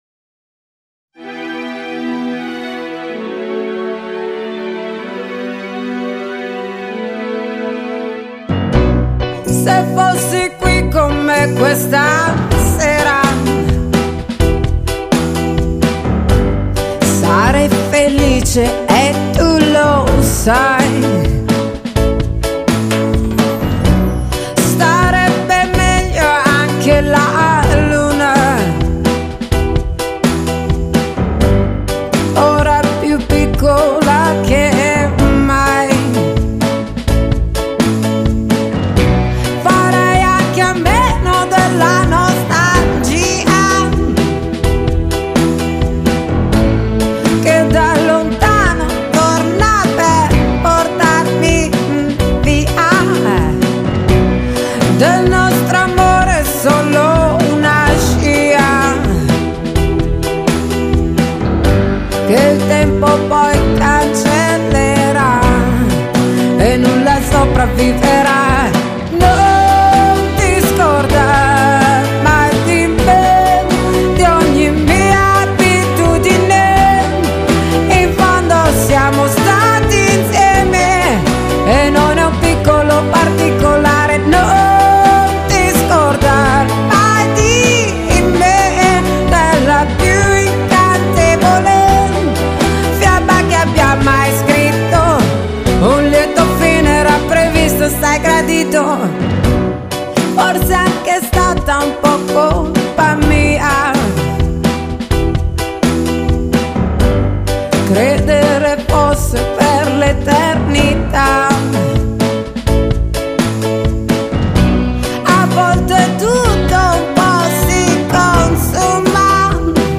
1979年出生的意大利新人女歌手，
唱出来的风格也非常特别,
沙哑的声音，乍一听，感觉不怎么好，
以流行音乐、蓝调、摇滚，还参杂点迷幻。